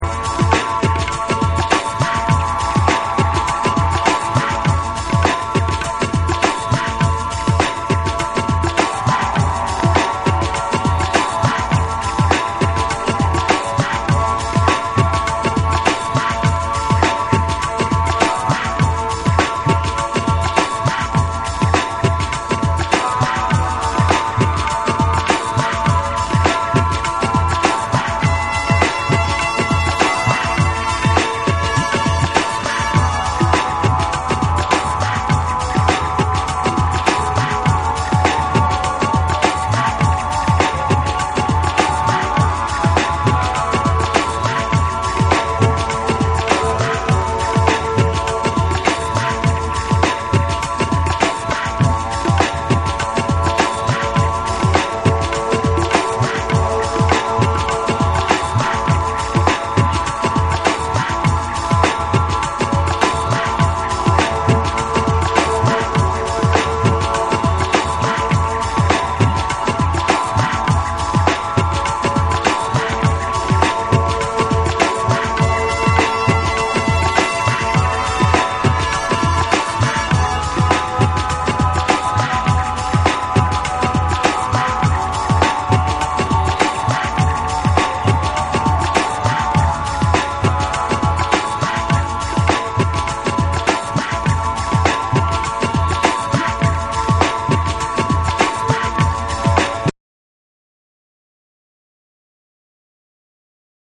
牧歌的な雰囲気が全体を包み込んだバレアリックナンバー3など収録したオススメ盤！
BREAKBEATS / ORGANIC GROOVE